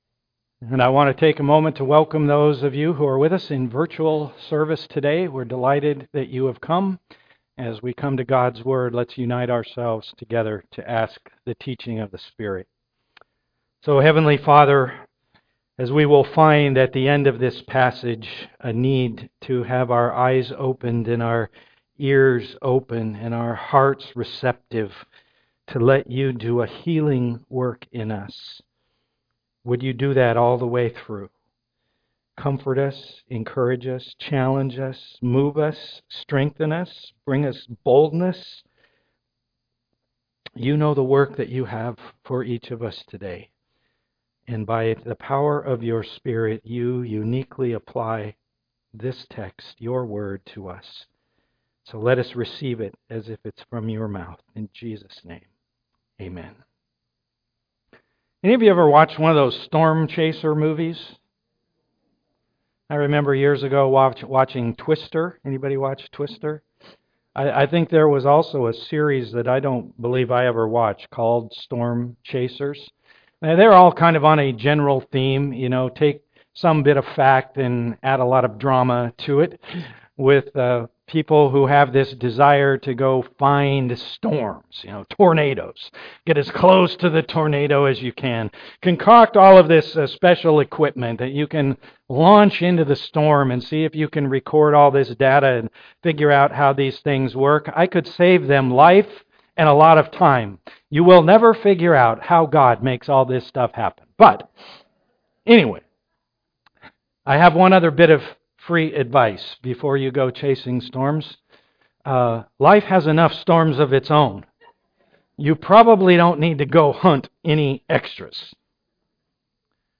Acts 28 Service Type: am worship Today we engage God's promise to Paul fulfilled as he arrives in Rome.